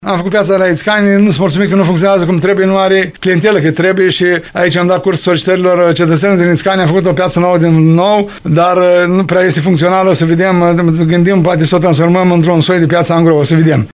LUNGU a declarat postului nostru de radio că o variantă eficientă ar fi transformarea pieței agroalimentare Ițcani în piață en-gross.